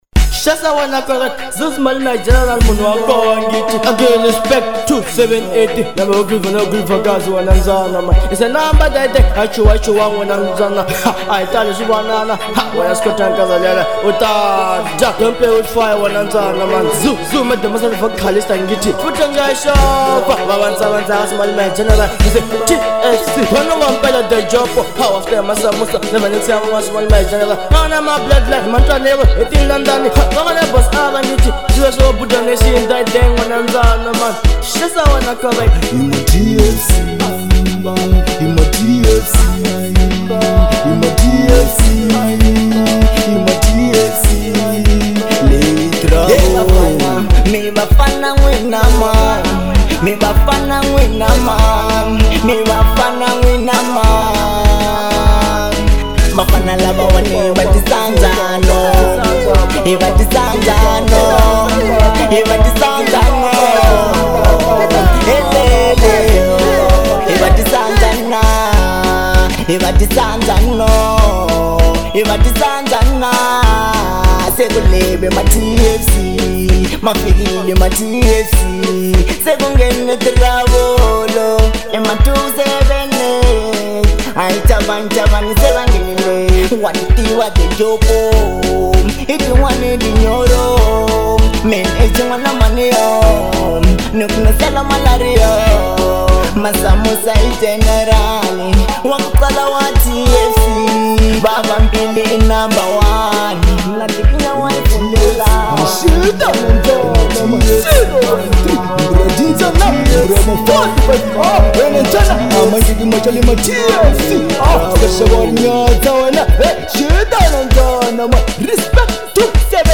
Genre : Manyalo